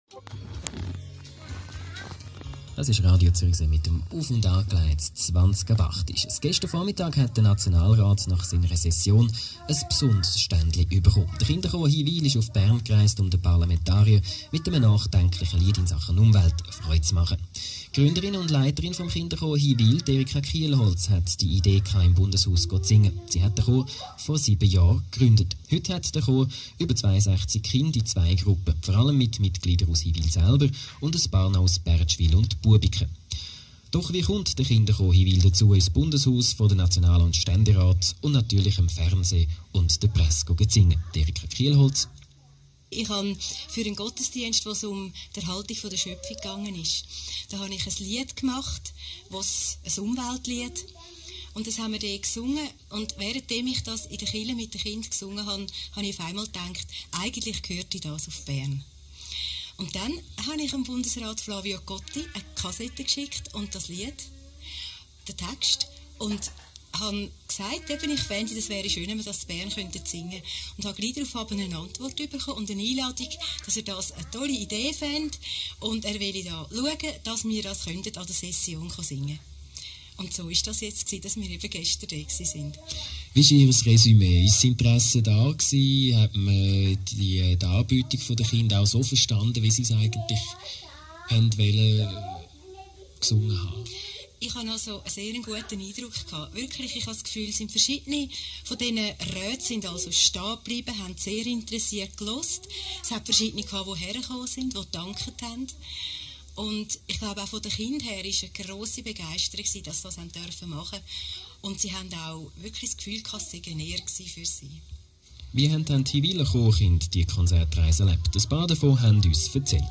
Interview
Stimmen aus dem Kinderchor